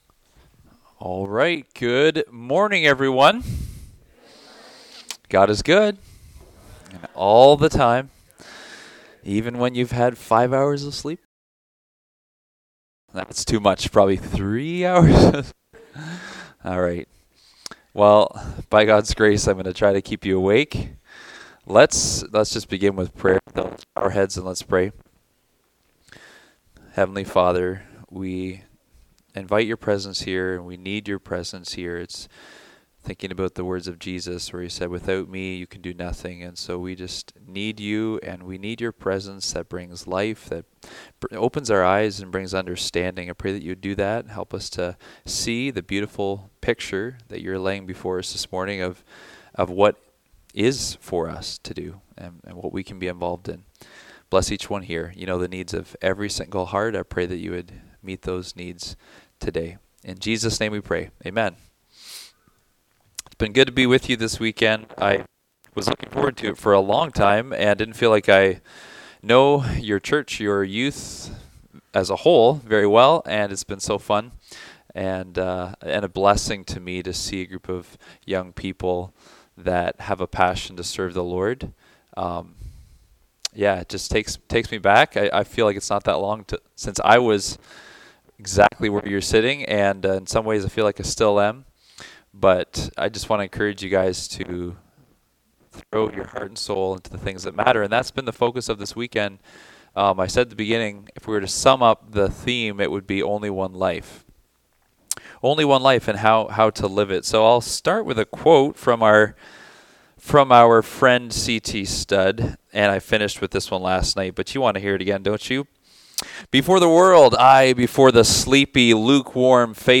Service Type: Youth Meetings